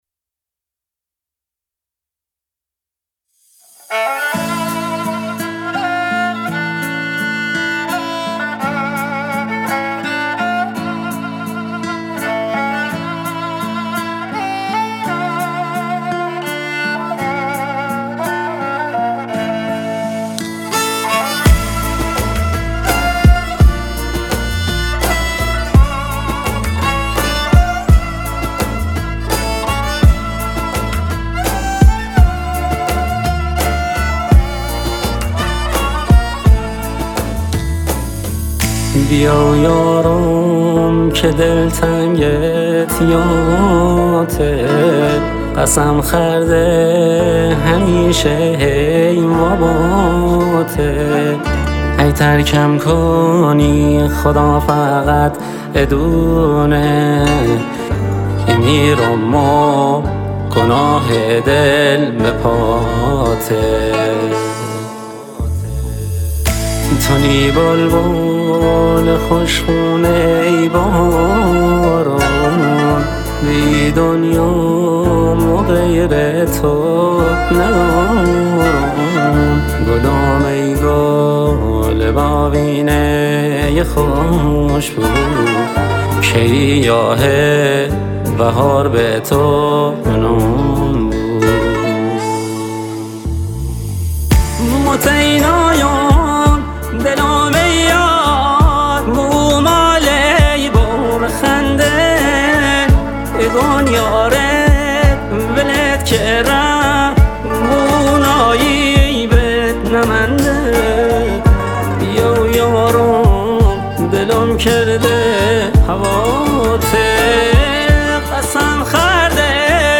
لری